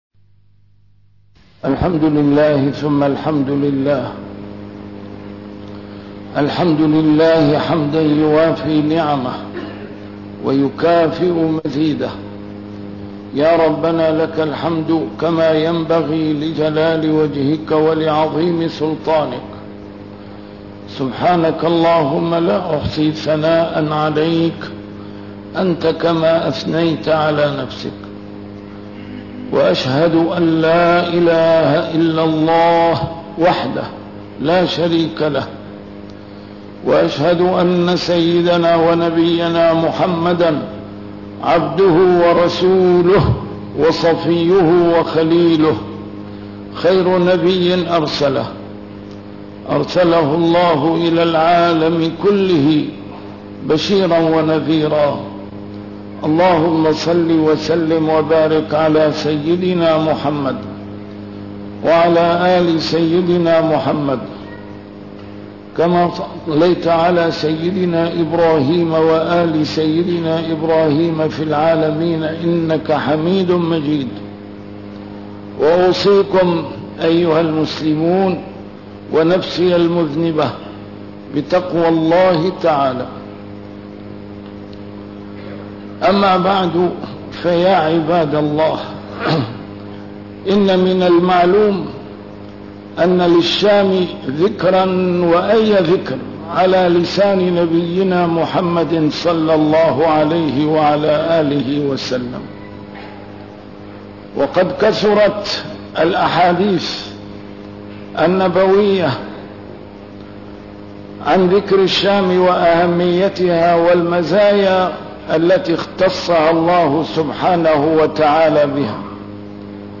A MARTYR SCHOLAR: IMAM MUHAMMAD SAEED RAMADAN AL-BOUTI - الخطب - الحصن الخفي